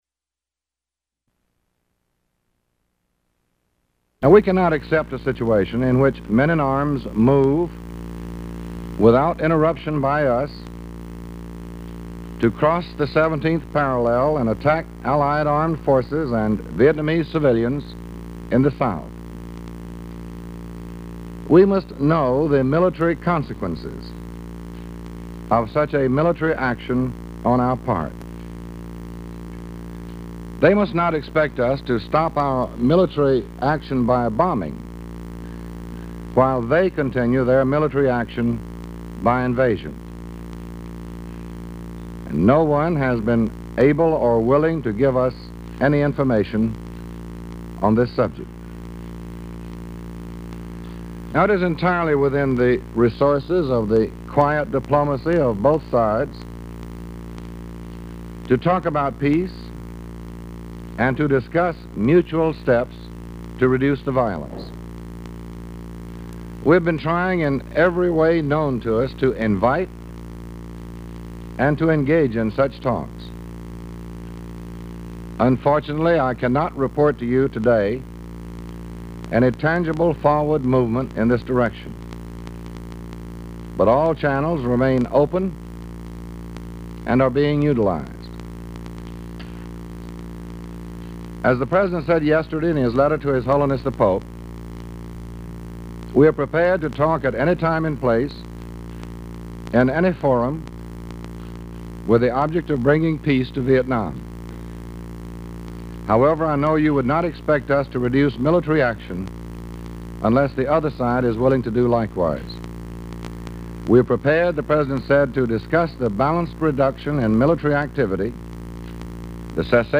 Excerpt of press conference at State Department, Washington, D.C